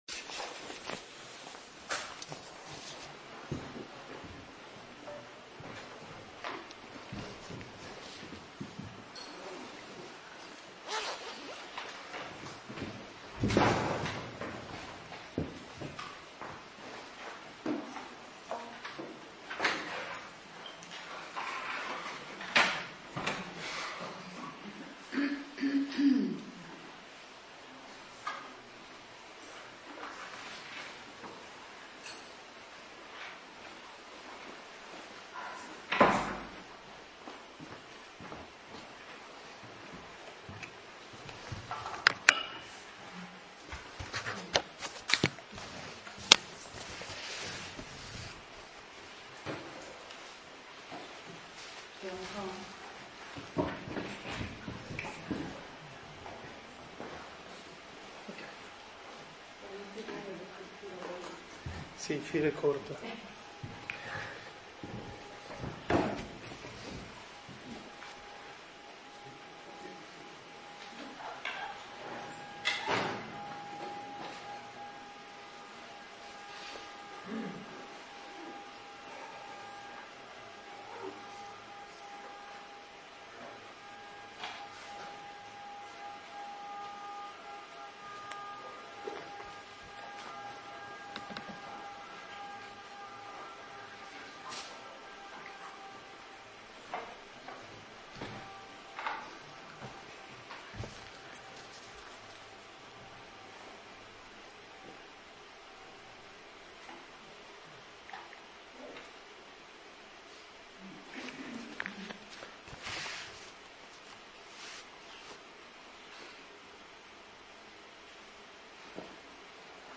Catechesi del 19 aprile 2025 – Anno C